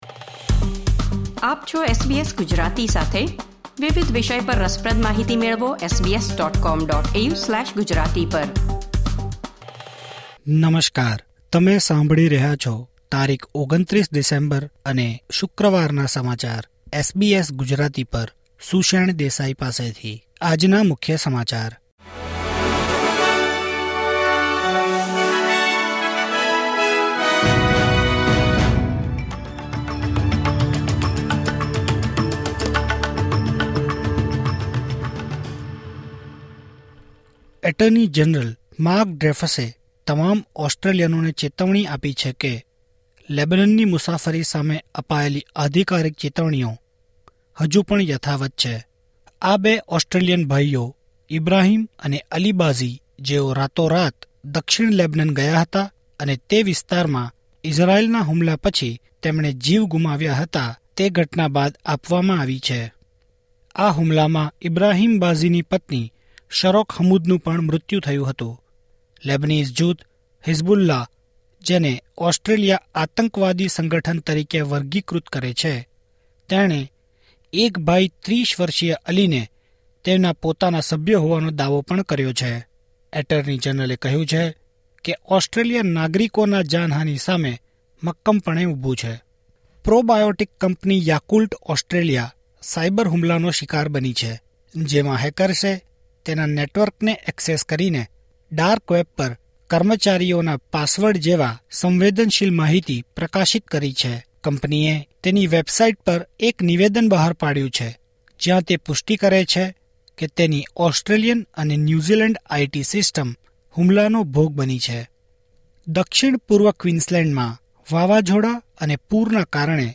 SBS Gujarati News Bulletin 29 December 2023